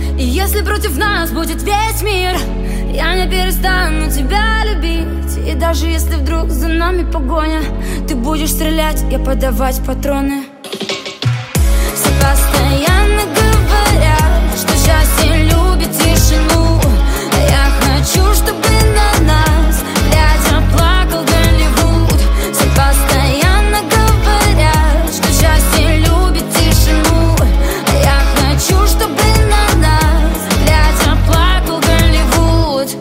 • Качество: 131, Stereo
поп